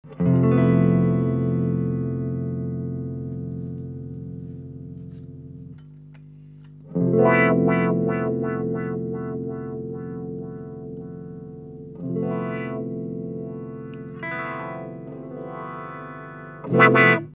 En fait, une wah comme son nom l’indique reproduit le son de l’onomatopée « Oua ».
La wah-wah
Wah.mp3